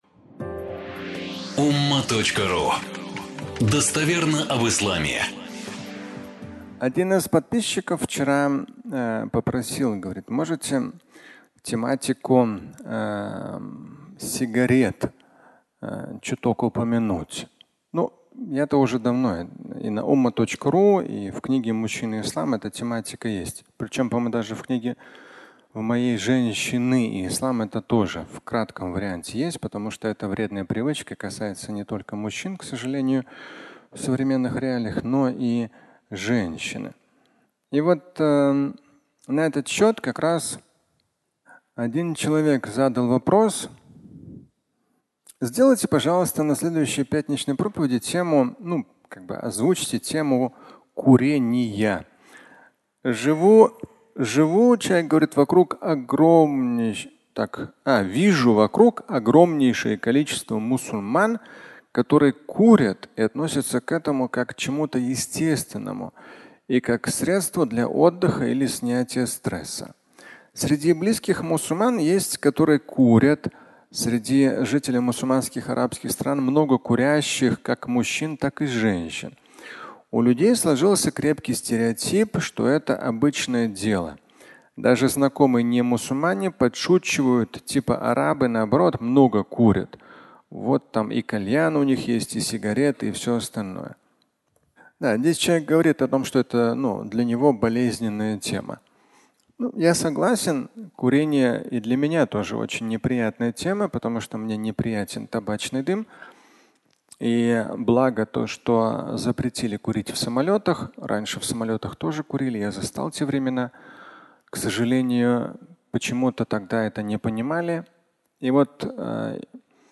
Фрагмент пятничной лекции, в котором Шамиль Аляутдинов говорит о пагубности вредных привычек.